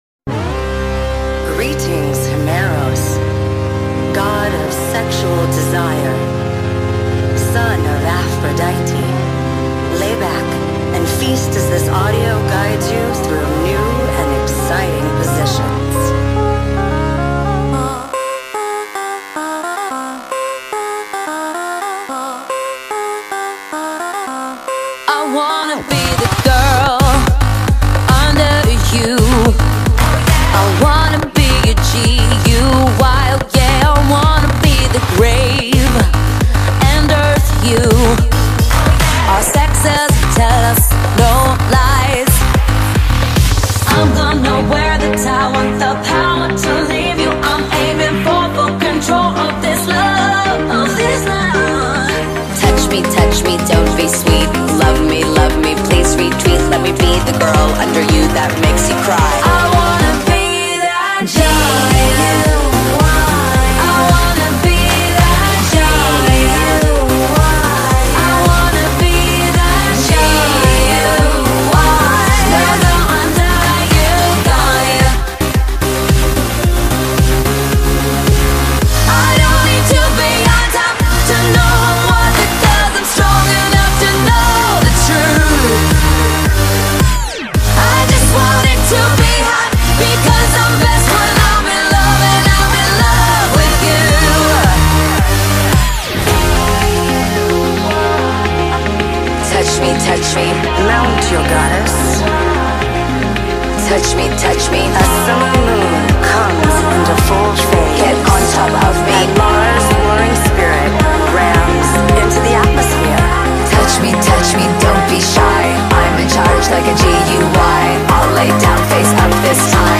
BPM110
Audio QualityCut From Video